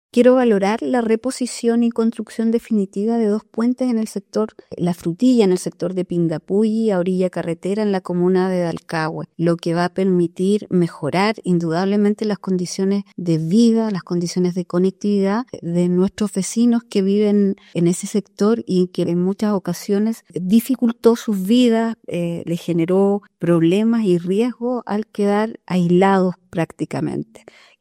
La alcaldesa de Dalcahue, Alejandra Villegas, destacó el impacto de la iniciativa que involucra a ambos viaductos.